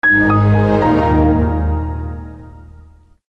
PLAY Money SoundFX